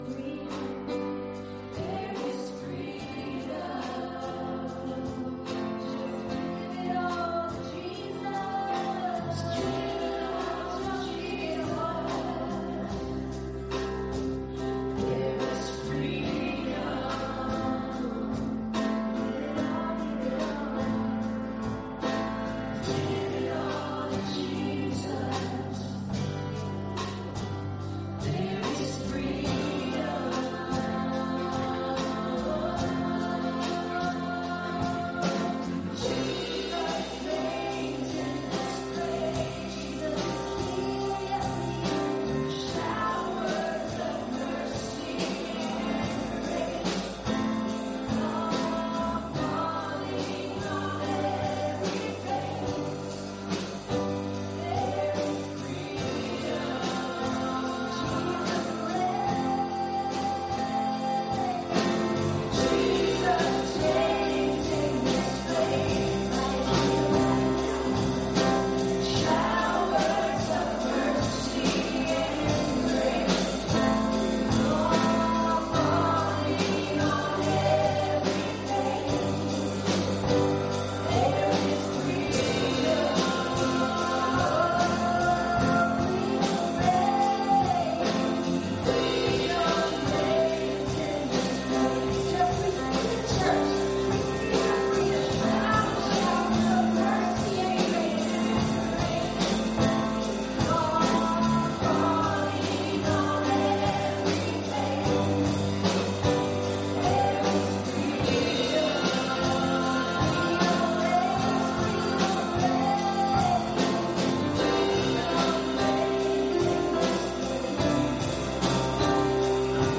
Worship Celebration -audio only